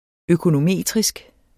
Udtale [ økonoˈmeˀtʁisg ]